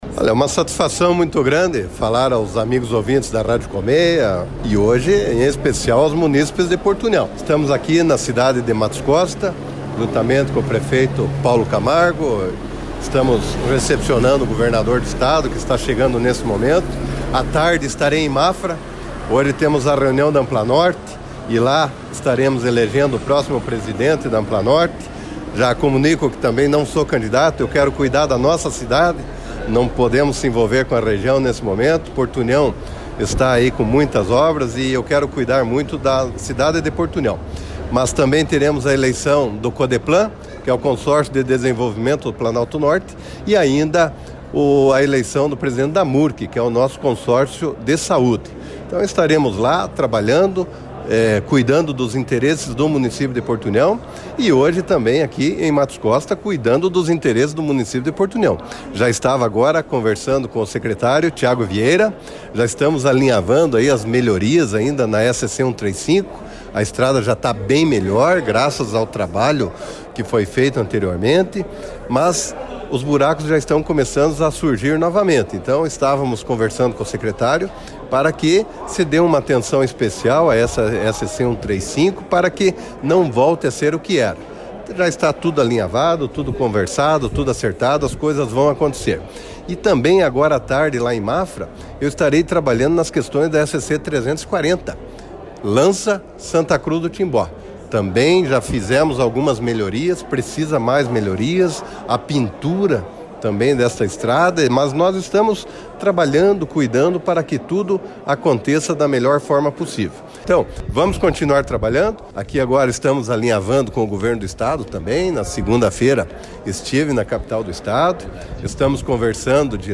Em relação a SC 135, trecho de Porto União a Matos Costa o prefeito de Porto União, Eliseu Mibach, frisou a atenção especial a rodovia.